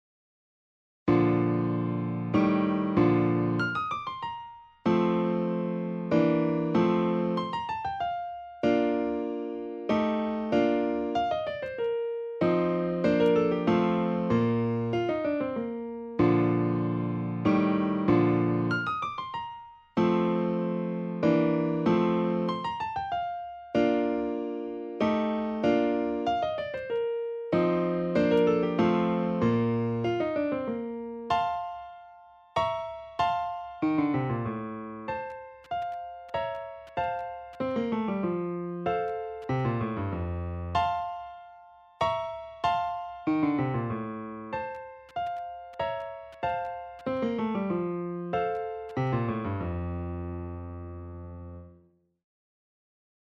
The music is created by electronic sounds and instruments, which are sequenced by musicians who become the author. The sounds, therefore, do not come from an orchestra, but the result is often pleasenty surprising.
This part of the trio is made of 13 measures for piano, with refrain.